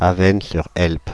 أفيسنيس سور هيلب ( نطق فرنسي: [avɛn syʁ ɛlp]  (
Fr-Avesnes-sur-Helpe.ogg